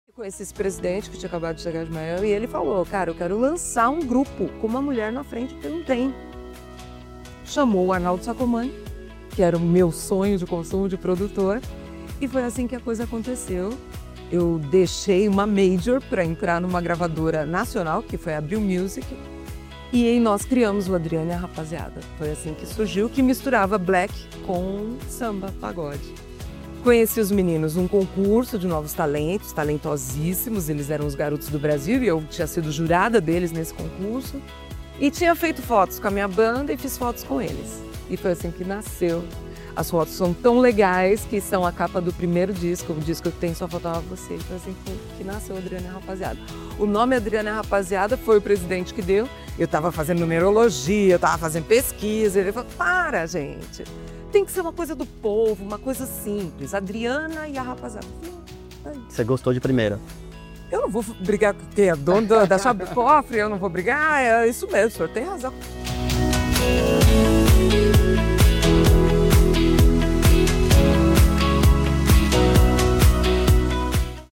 Um encontro cheio de música, memória e emoção.